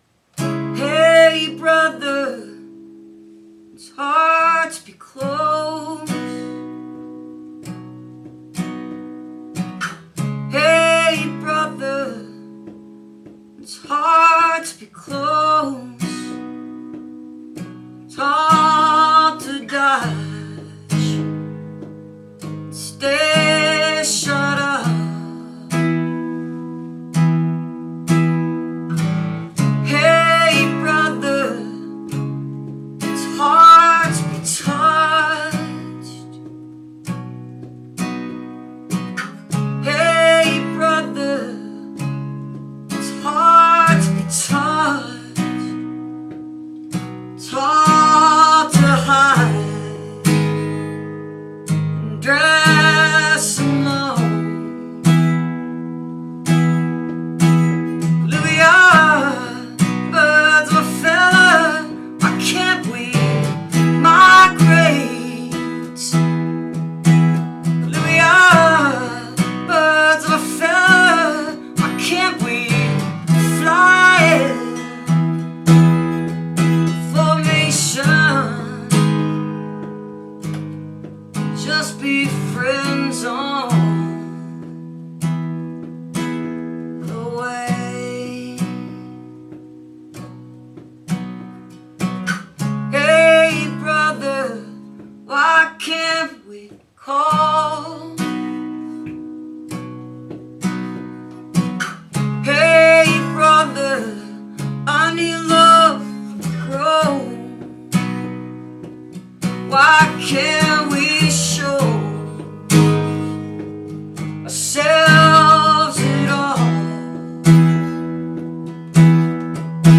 2008. all the performances are acoustic